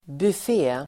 Uttal: [buf'e:]